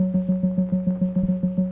Index of /90_sSampleCDs/Keyboards of The 60's and 70's - CD1/KEY_Optigan/KEY_Optigan Keys